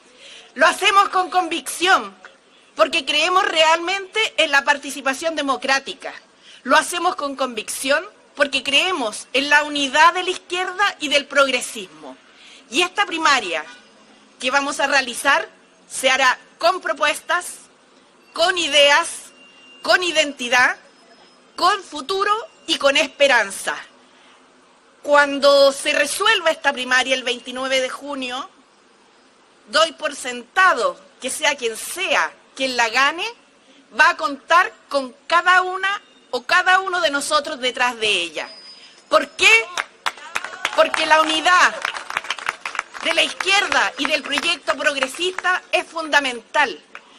Por su parte, la ex ministra Jeannette Jara, carta del Partido Comunista, destacó que en este proceso “será la ciudadanía quien elija de manera participativa, transparente y en el marco de la democracia” al representante del sector.